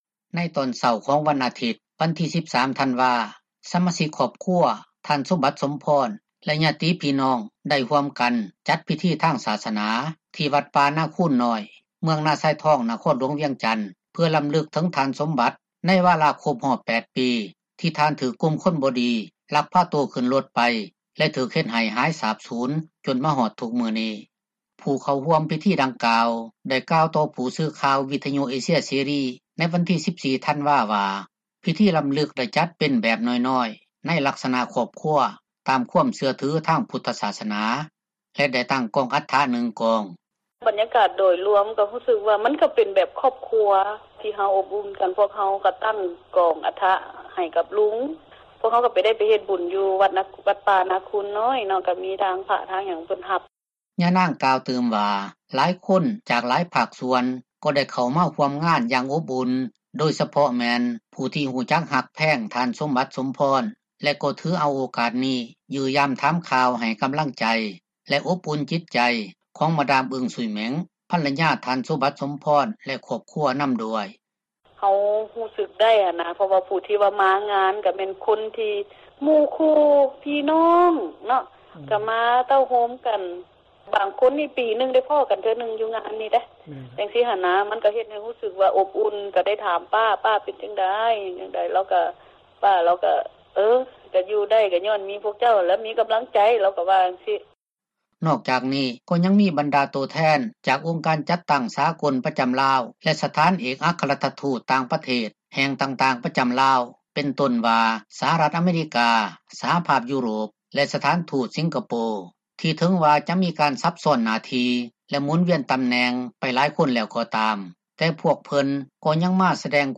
ກ່ຽວກັບການຫາຍສາບສູນ ຂອງ ທ່ານ ສົມບັດ ສົມພອນ ເປັນເວລາ 8 ປີ ໃນປີນີ້, ຊາວໜຸ່ມລາວຄົນນຶ່ງ ກໍໄດ້ກ່າວ ຕໍ່ຜູ້ສື່ຂ່າວ ວິທຍຸ ເອເຊັຽເສຣີ ໃນວັນທີ 13 ທັນວາ ວ່າ ທ່ານຮູ້ສຶກ ເຫັນໃຈຄອບຄົວ ແລະ ພັລຍາ ທ່ານ ສົມບັດ ສົມພອນ ທີ່ສຸດ ແລະ ຄົງບໍ່ມີໃຜຮັບໄດ້ ຖ້າຫາກເກີດສິ່ງນີ້ກັບຄອບຄົວໂຕເອງ.